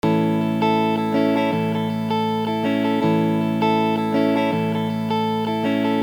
پکیج ریتم و استرام گیتار الکتریک ایرانی
دموی صوتی ریتم چهار چهارم (رایت) :